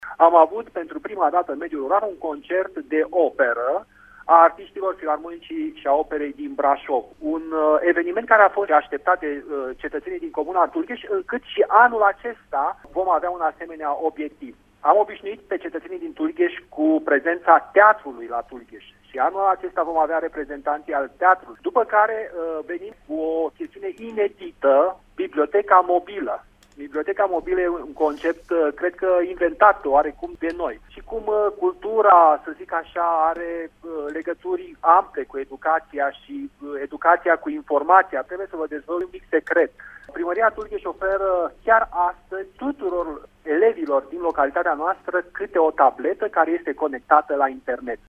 Locul 2 a fost ocupat de Sângeorgiu de Mureș, dar și localitatea Tulgheș din Harghita s-a numărat printre cele mai frumoase sate culturale, spune primarul localității, Marcel Vancu:
primarul-din-Tulghes.mp3